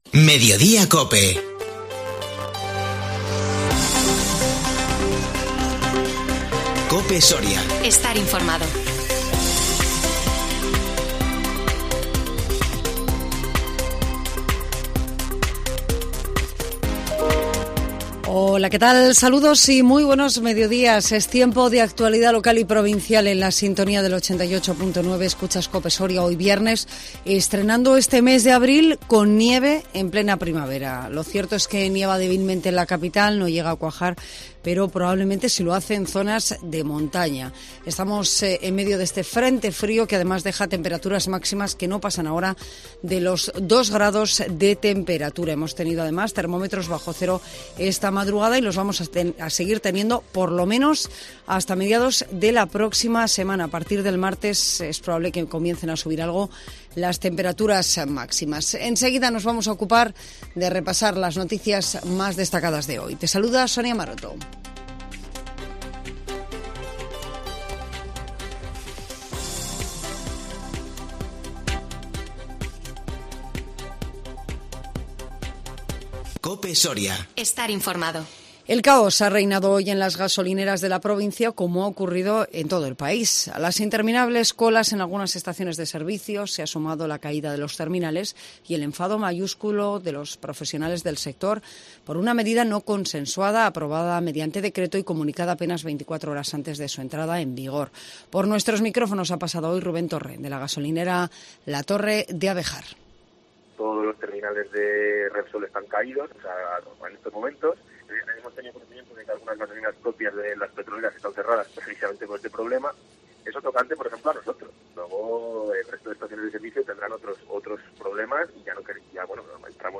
INFORMATIVO MEDIODÍA COPE SORIA 1 ABRIL 2022